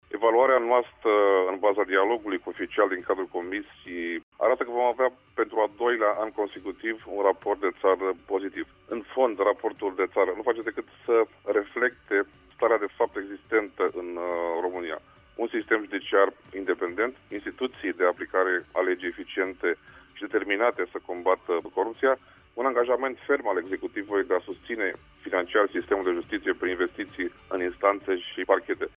Robert Cazanciuc a explicat astăzi, la Radio România Actualităţi, că estimarea se bazează pe evaluarea făcută la nivelul Ministerului după discuţiile avute recent cu oficialităţi europene.